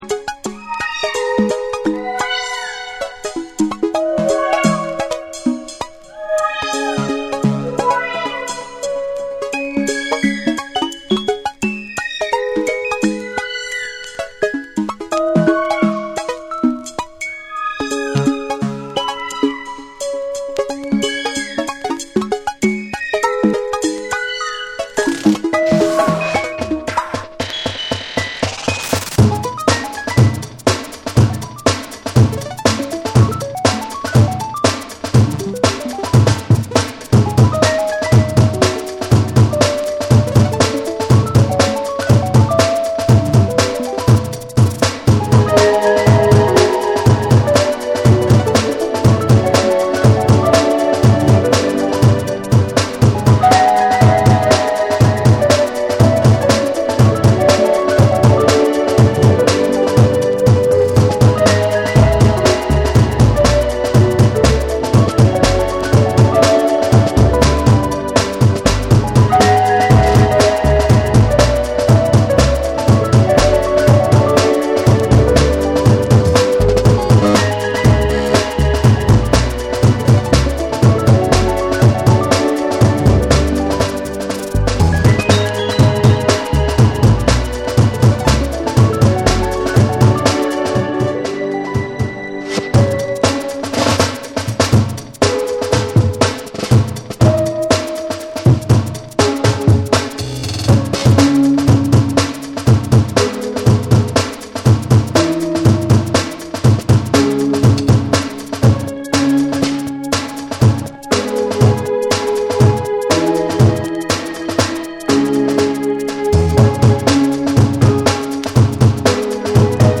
複雑に組み上げられた緻密なビートと、温かみあるメロディが共存する
流麗かつ幻想的なサウンドスケープを描く
BREAKBEATS